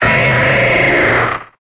Cri de Linéon dans Pokémon Épée et Bouclier.